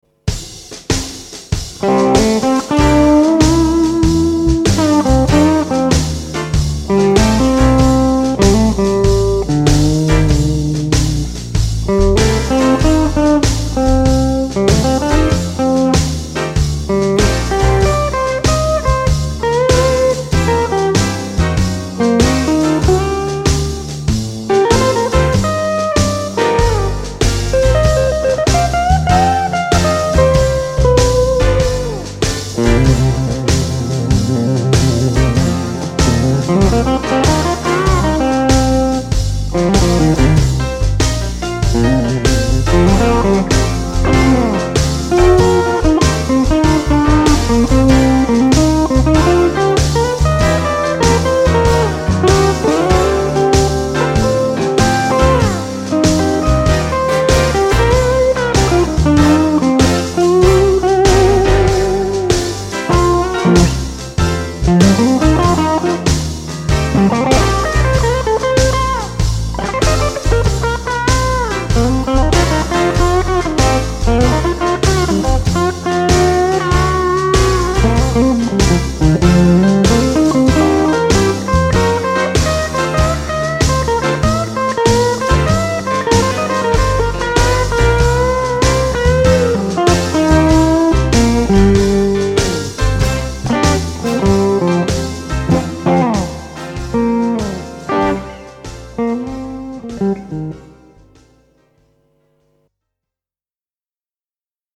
Tarjolla olisi T-Bone Walker tyylistä höttöä.
- kun osallistut, soita soolo annetun taustan päälle ja pistä linkki tähän threadiin.